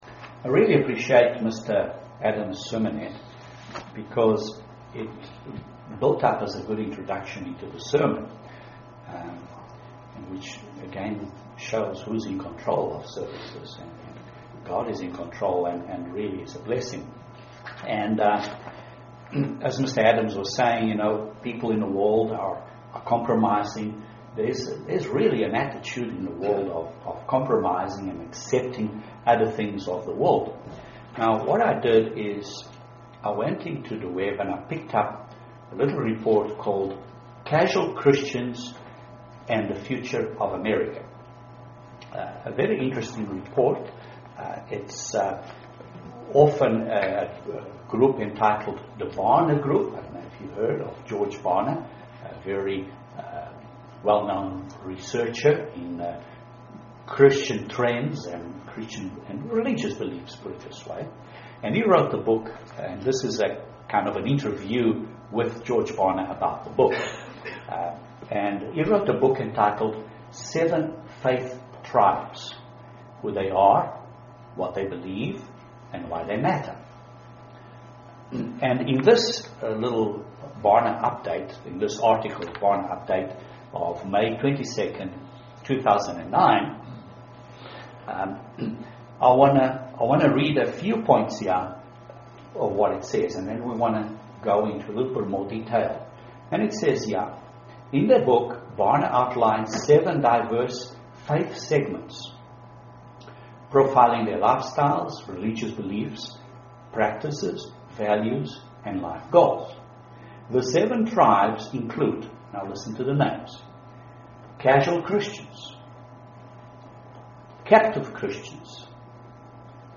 Some pointers to beware so that we don't become casual Christians UCG Sermon Transcript This transcript was generated by AI and may contain errors.